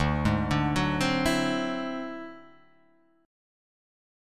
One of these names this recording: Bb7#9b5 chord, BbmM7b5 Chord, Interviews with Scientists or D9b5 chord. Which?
D9b5 chord